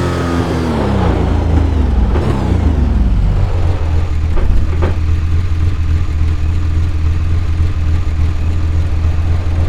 Index of /server/sound/vehicles/lwcars/lotus_esprit
slowdown_slow.wav